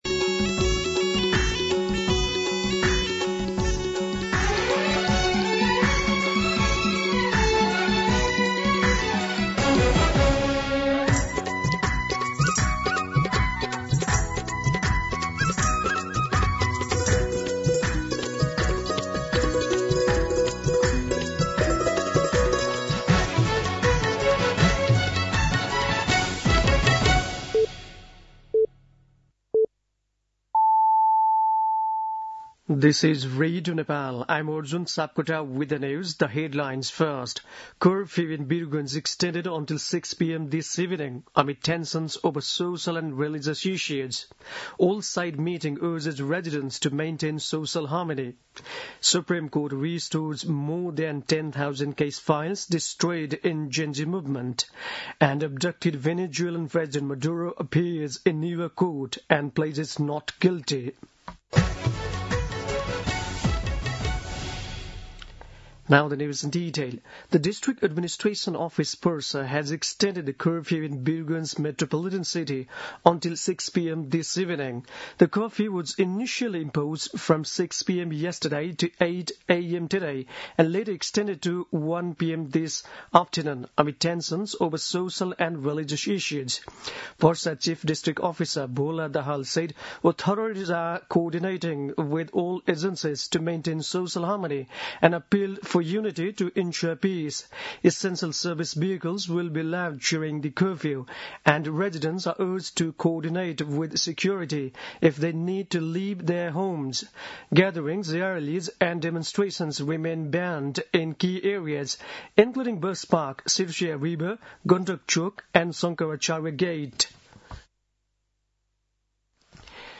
दिउँसो २ बजेको अङ्ग्रेजी समाचार : २२ पुष , २०८२